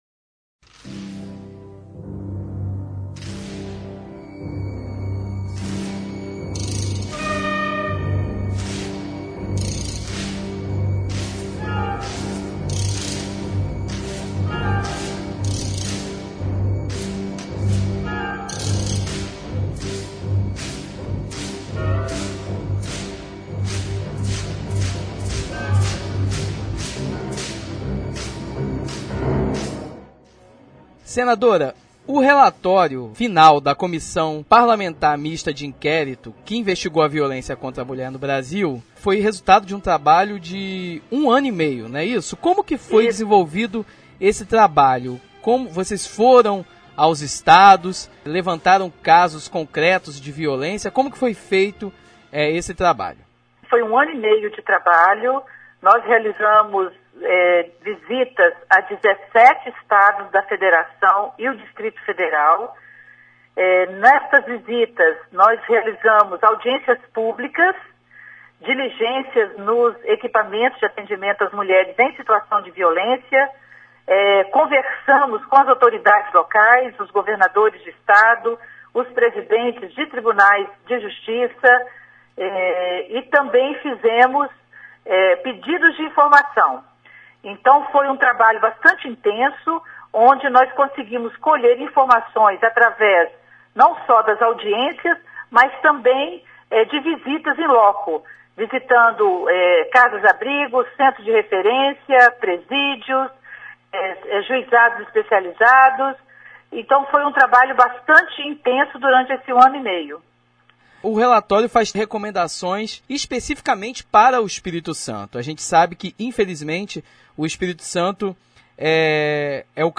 Confira a entrevista que ela concedeu ao Revista Universitária na quinta-feira (8) falando sobre o relatório. Entrevista senadora Ana Rita Download : Entrevista senadora Ana Rita